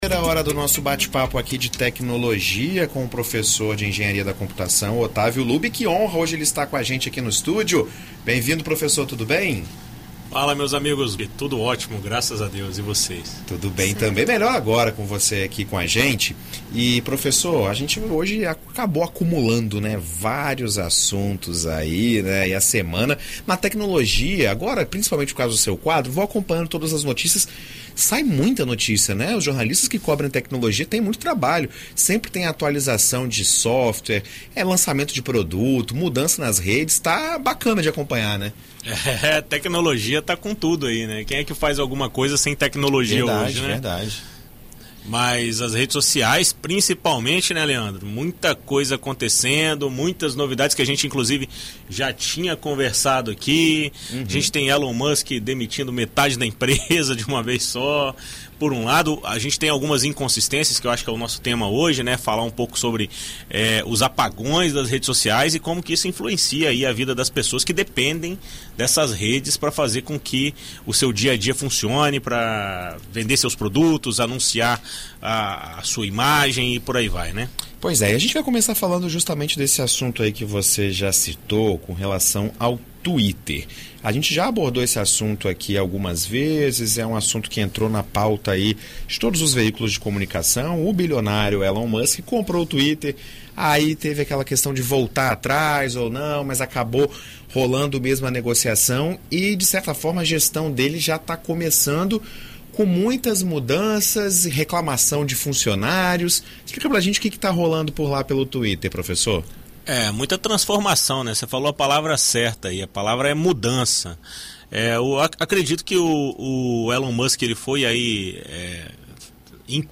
Em entrevista à BandNews FM Espírito Santo nesta terça-feira (08)